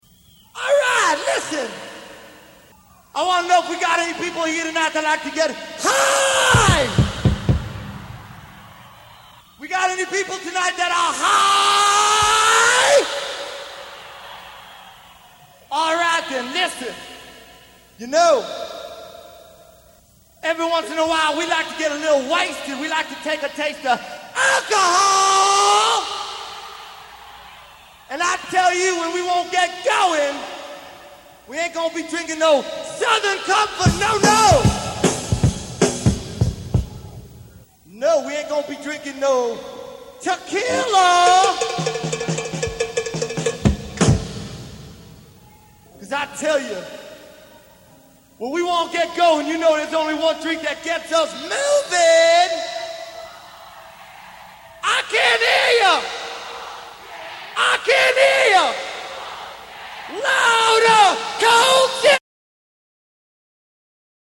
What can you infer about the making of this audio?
On this day in 1983 KISS performed at the Convention Center in Pine Bluff, Arkansas.